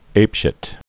(āpshĭt)